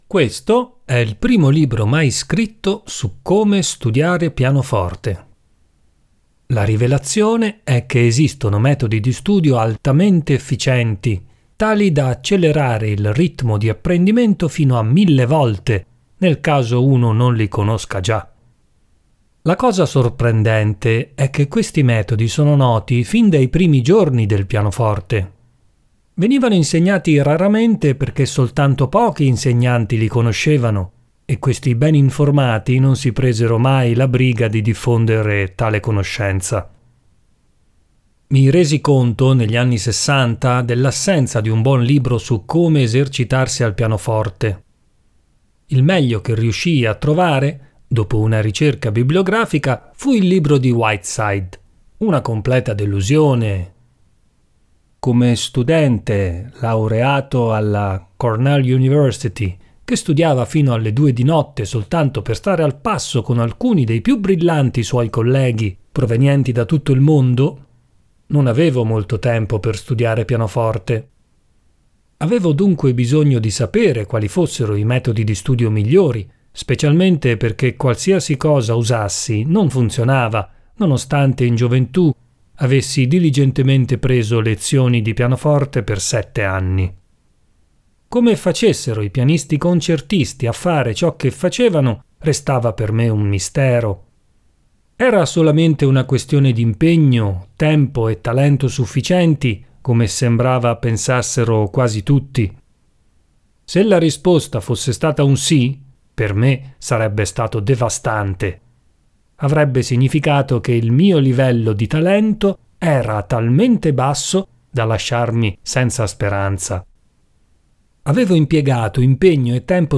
ASCOLTA LA AUDIOLETTURA DELLA PREFAZIONE: (Durata 56 minuti)